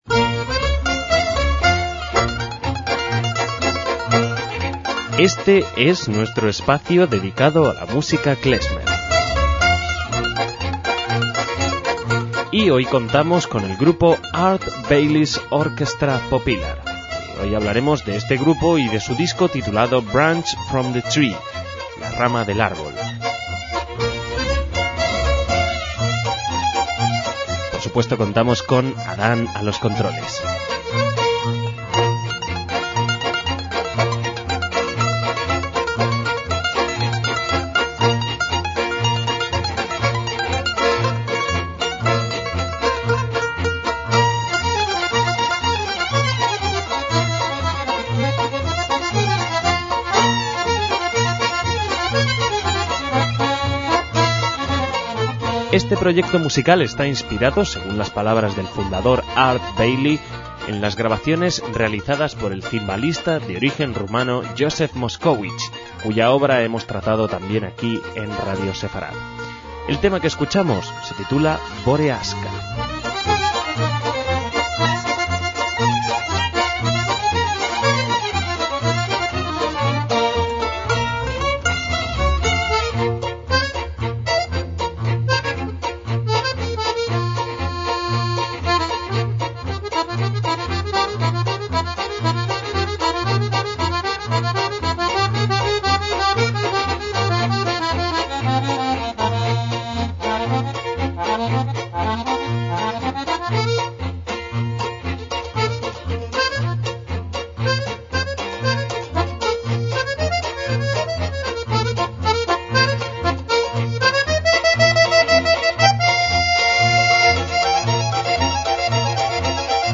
MÚSICA KLEZMER